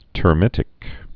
(tər-mĭtĭk)